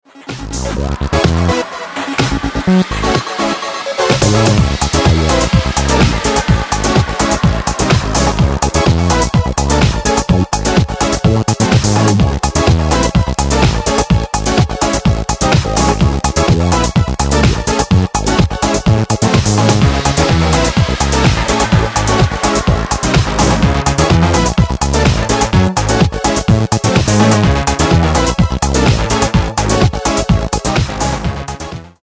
He makes freaked out Italo Disco that’s ahead of its time.